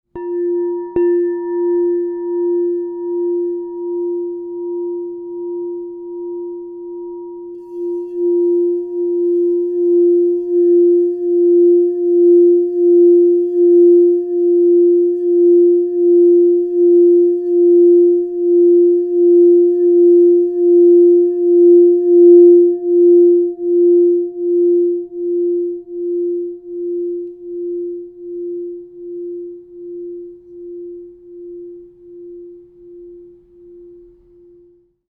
Rose Quartz, White Light Aura Gold 6" F 0 Crystal Tones singing bowl
Immerse yourself in the loving resonance of the Crystal Tones® Rose Quartz Double Alchemy True Tone 6 inch F Singing Bowl, resonating at a perfect F 0 True Tone to inspire balance, clarity, and emotional healing.
The compact 6-inch size delivers focused and resonant tones, perfect for personal practice or enhancing sacred spaces.
440Hz (TrueTone)